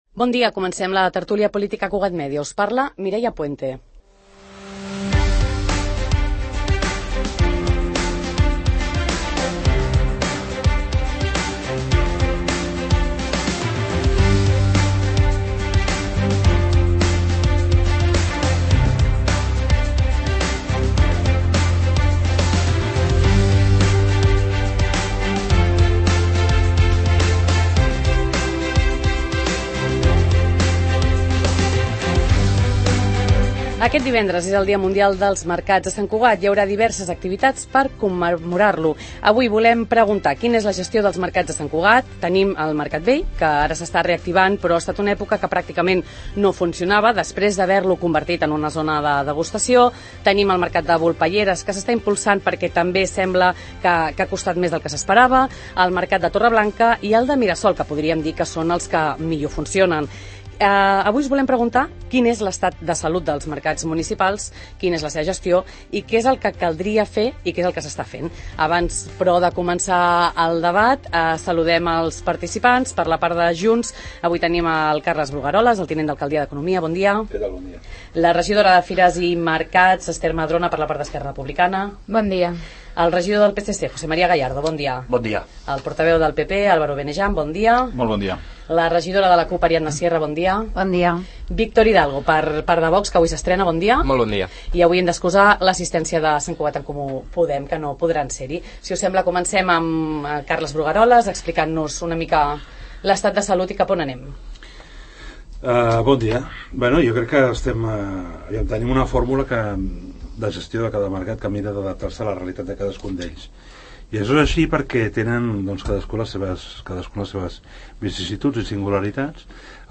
Els Mercats Municipals, a la tert�lia pol�tica de R�dio Sant Cugat